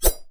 Sword3.wav